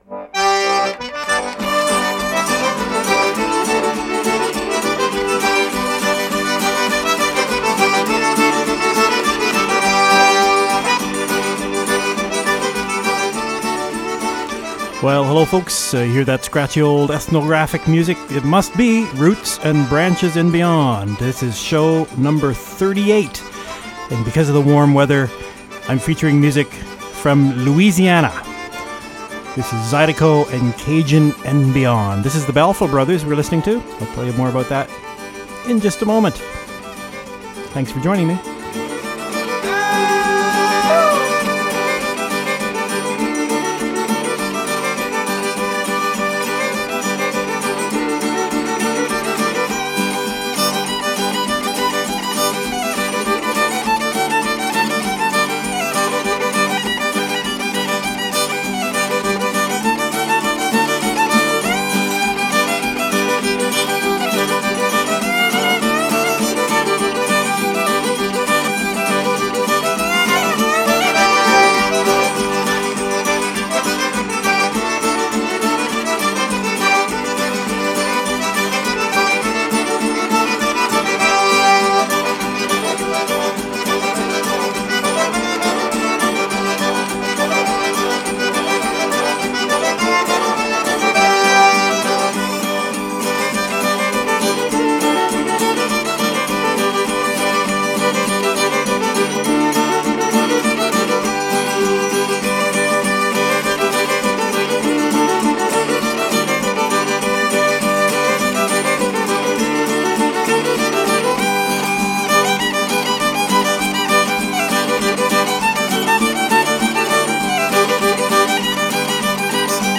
Cajun & Zydeco & Beyond